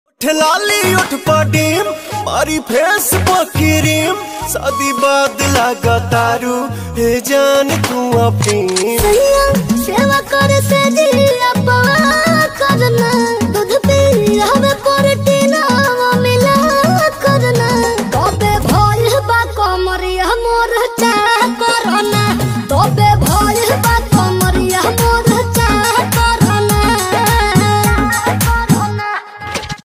bhojpuri ringtone